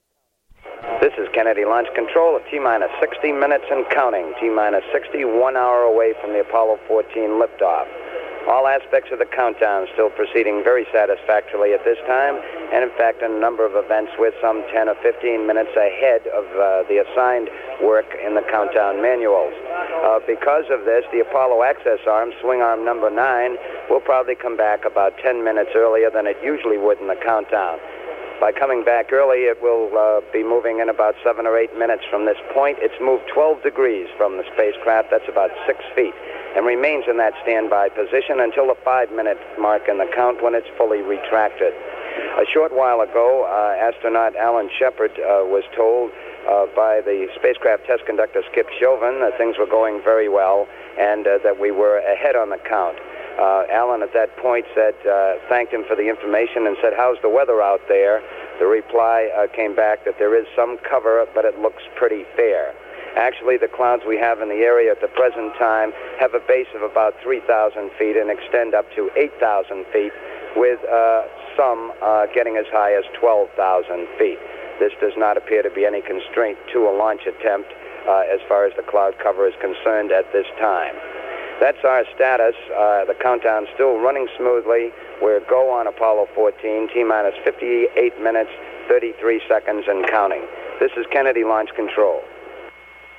PAO loop.